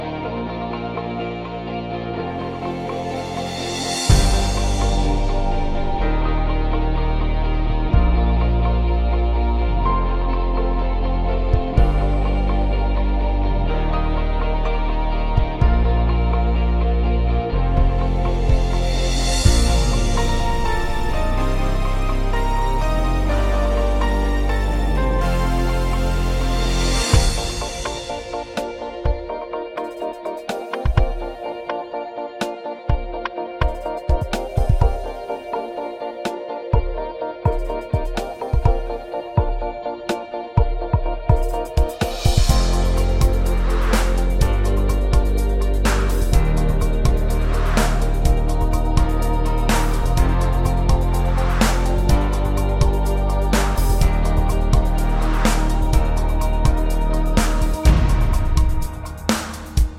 Down 2 Semitones Musicals 3:27 Buy £1.50